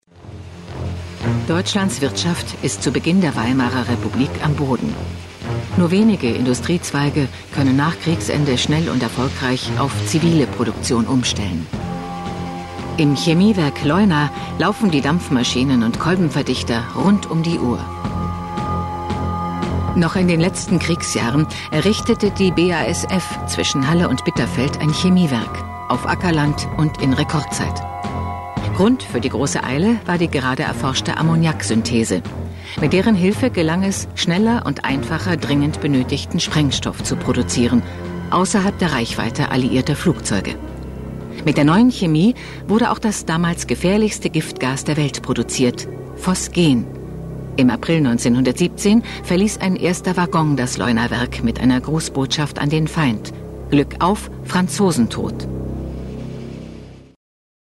Deutsche Profisprecherin.
Stimme mit großer Wandlungsfähigkeit. Warme Alt-Stimme, Kompetenz in der Stimme. Reife Stimme.
Sprechprobe: Industrie (Muttersprache):
German female voice over talent